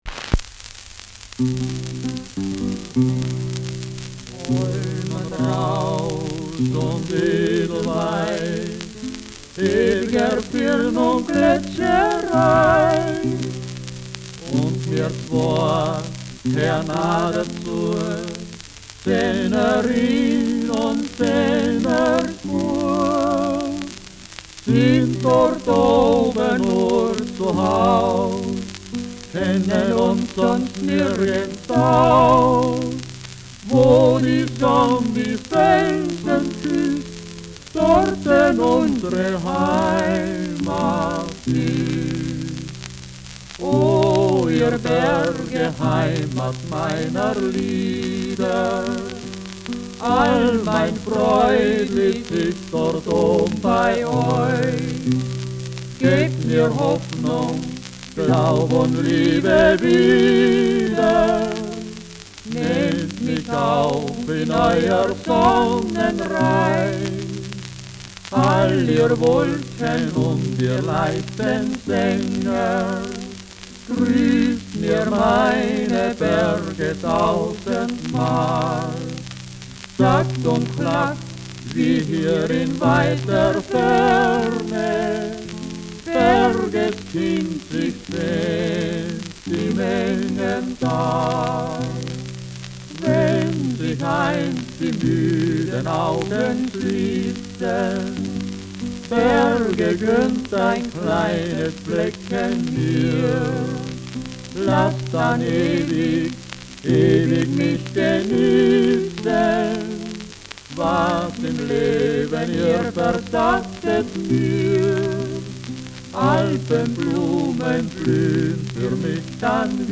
Schellackplatte
Tonrille: Kratzer Durchgehend Leicht
Vereinzelt leichtes Knacken
mit Lautenbegleitung
[Berlin] (Aufnahmeort)
Folkloristisches Ensemble* FVS-00015